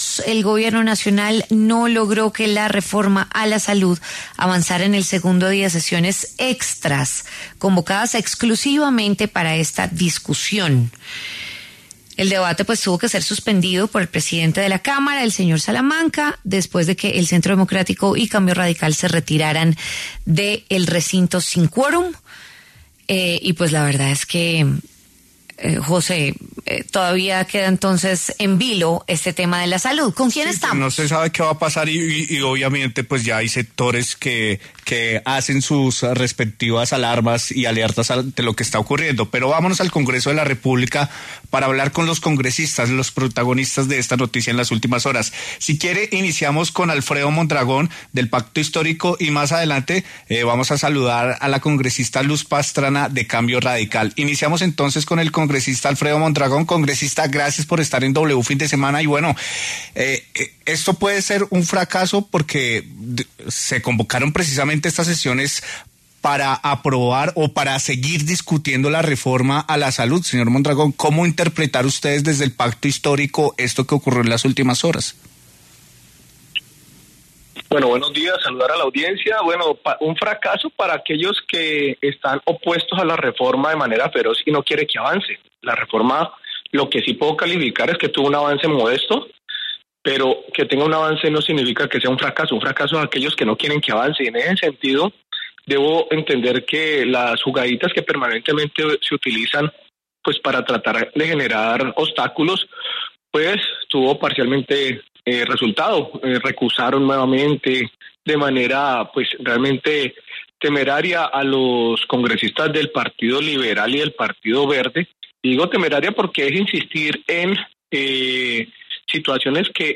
Los congresistas Alfredo Mondragón y Luz Pastrana, debatieron en W Fin de Semana sobre la reforma a la salud que no avanzó en el segundo día de sesiones extras.